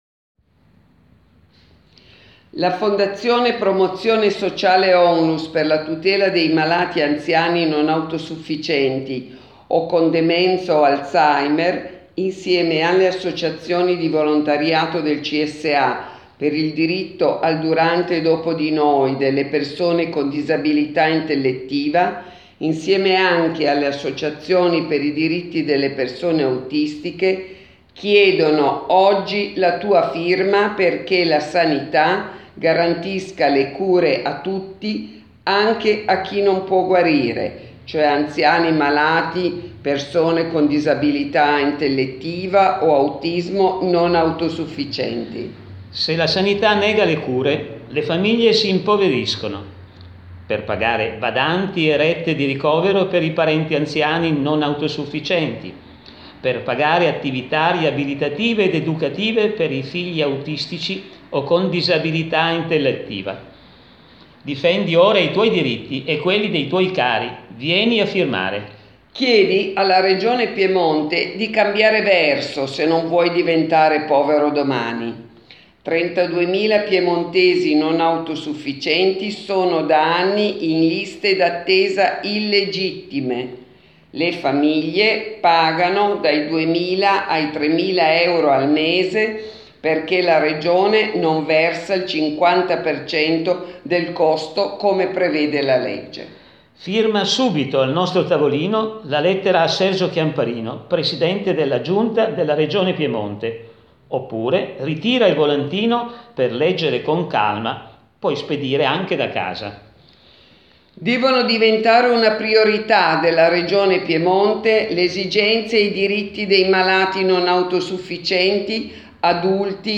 2016_Registrazione_presidio.m4a